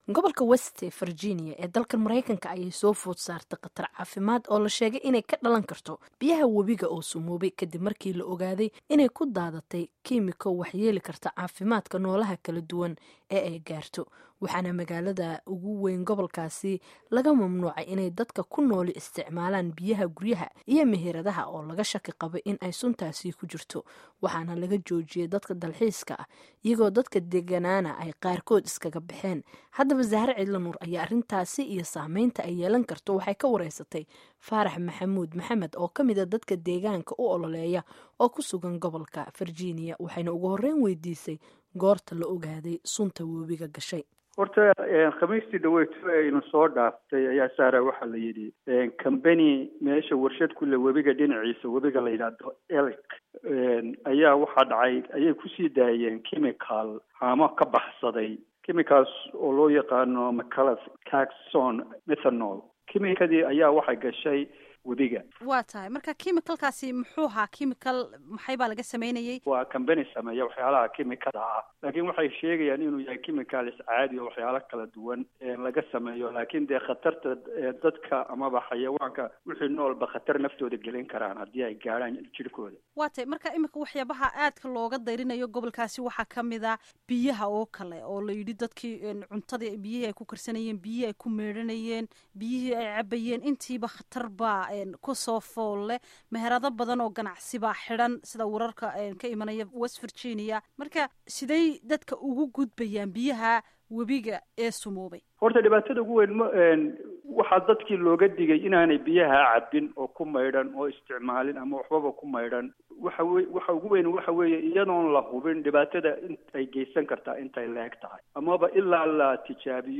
Dhageyso Warbixinta Sunta ku daadatay Wabiga West Virginia